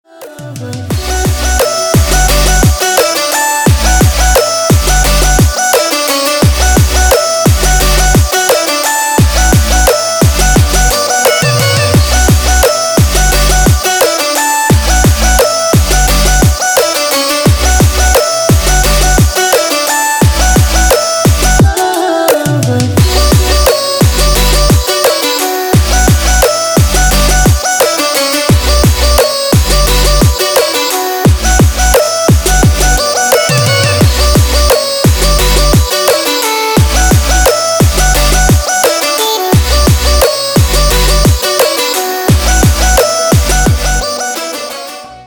громкие
Melodic dubstep
стиль: Melodic Dubstep